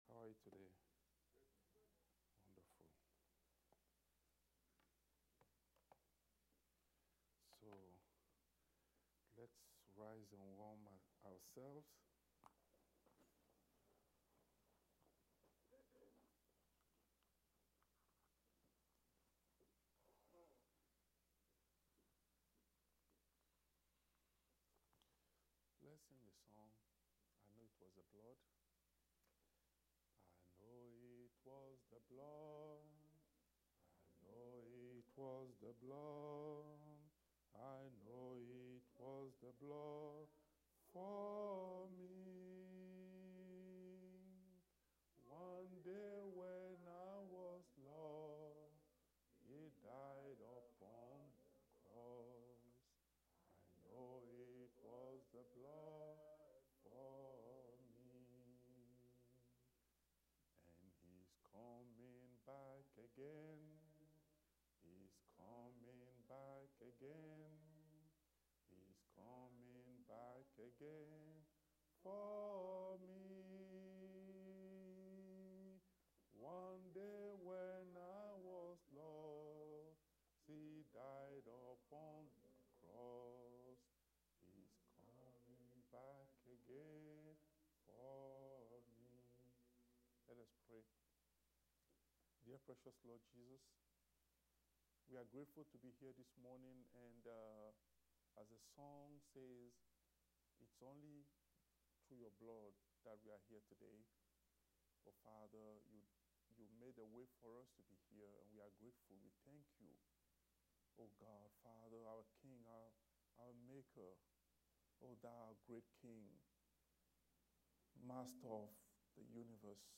Series: Sunday school